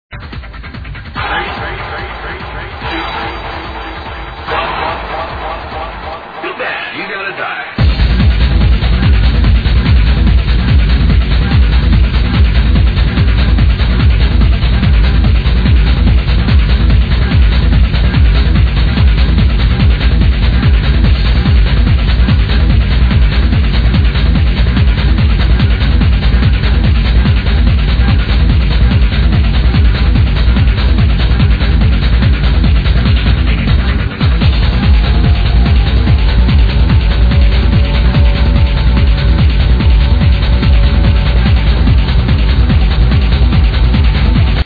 help me id this hard tribal track
now this is hard!!!and as strange as it sounds, its good